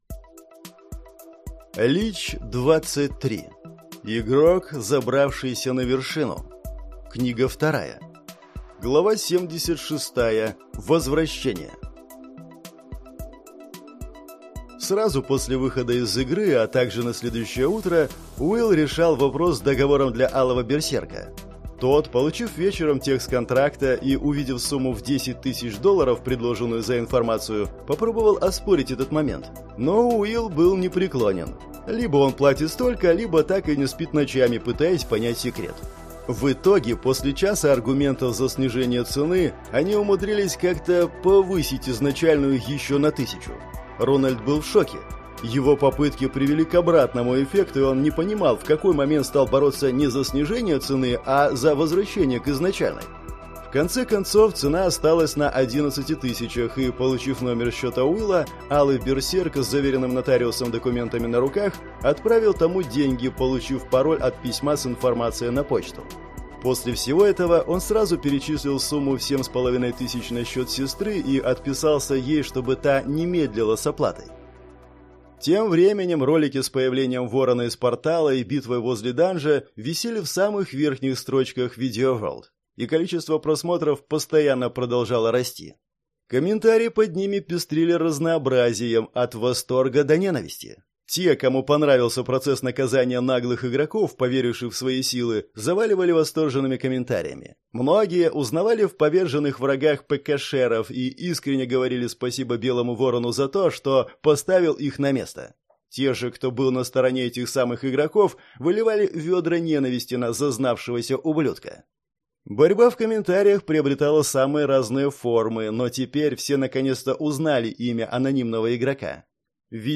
Аудиокнига Игрок, забравшийся на вершину. Книга 2 | Библиотека аудиокниг